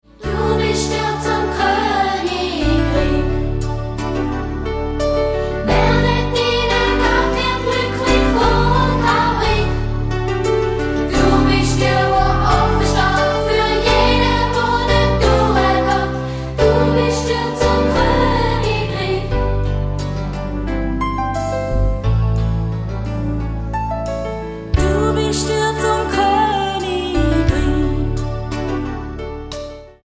die vielen kurzen, eingängigen Refrains